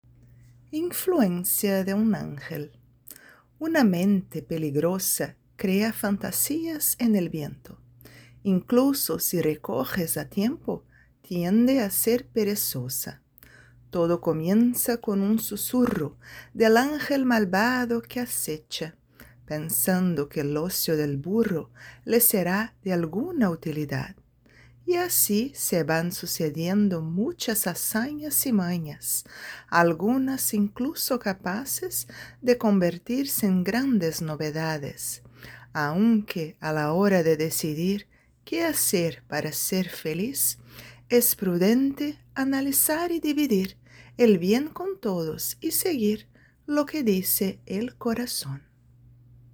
Poesías